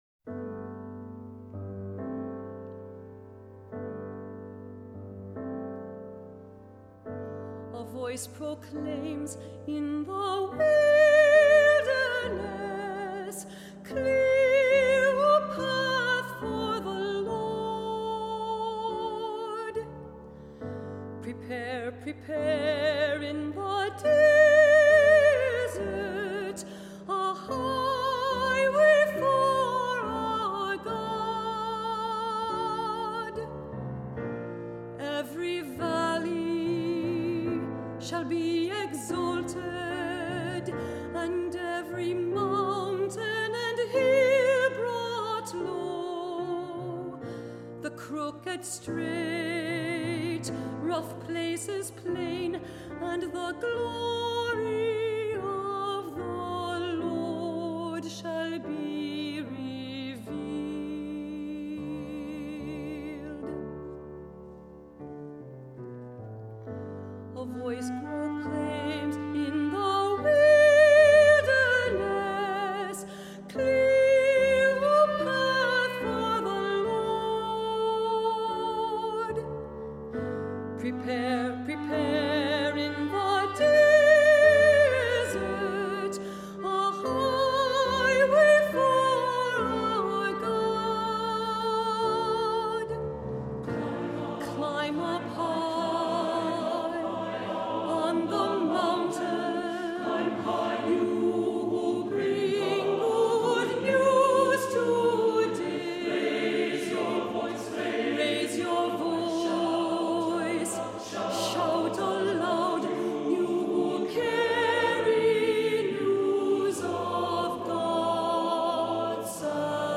Accompaniment:      Keyboard, Solo
Music Category:      Choral
Clarinet B-flat or B-flat Soprano Saxophone.